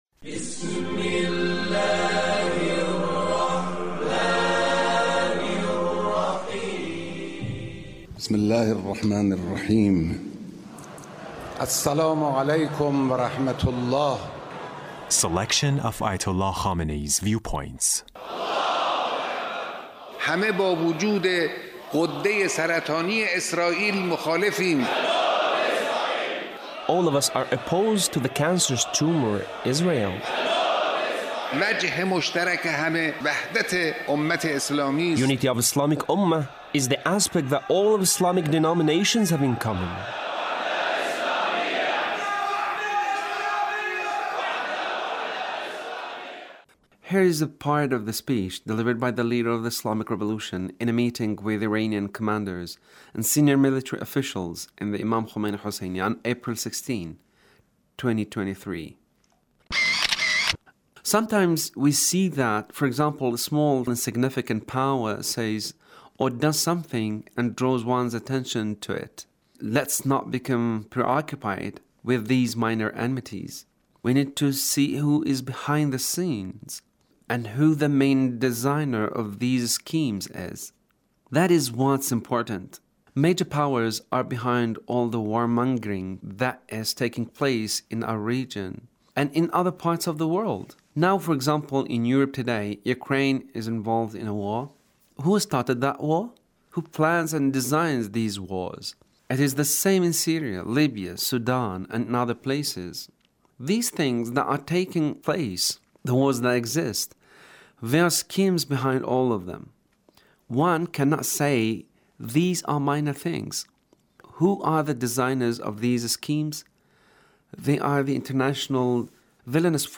Leader's Speech (1722)
Leader's Speech Revolution in a meeting with Iranian commanders and senior military officials